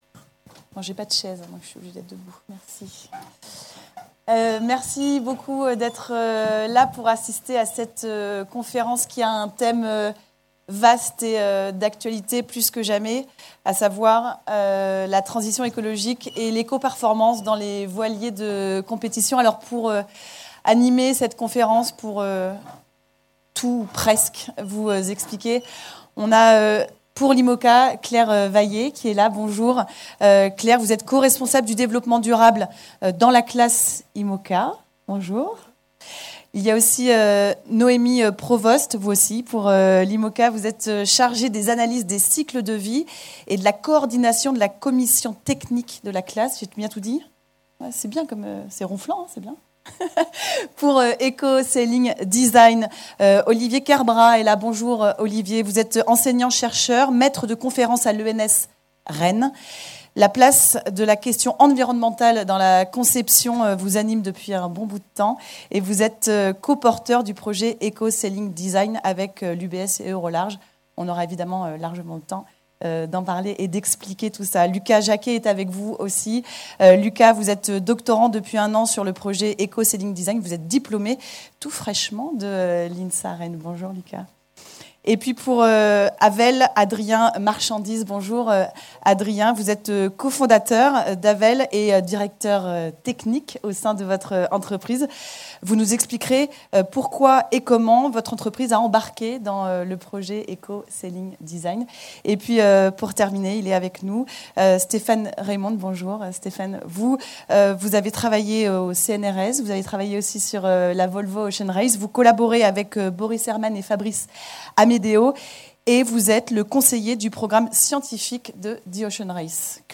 Au-delà des prouesses sportives, l’évènement a également laissé la part belle aux échanges via un cycle de conférence dans l’auditorium de la Cité de la Voile Eric Tabarly, pour penser collectivement les grands défis technologiques de la course au large et ses enjeux environnementaux.